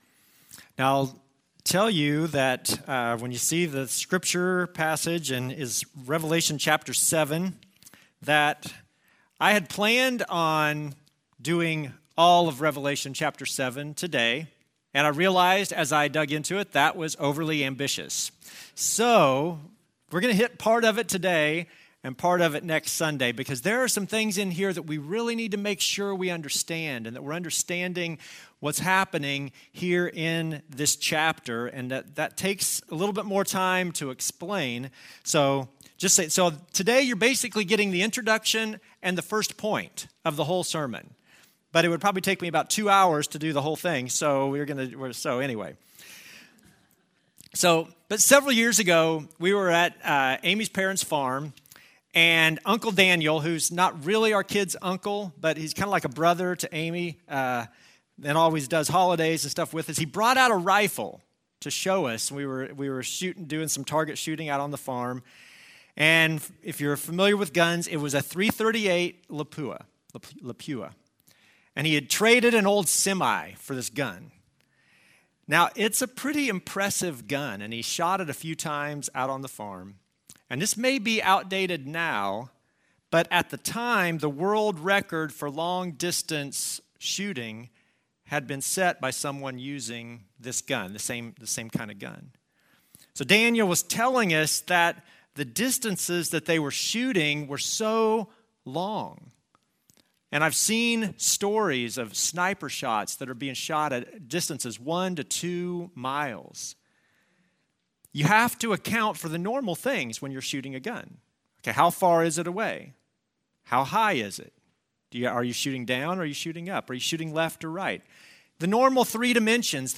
Passage: Revelation 7 Service Type: Normal service